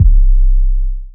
808s
LEX Trunk Shaker(6).wav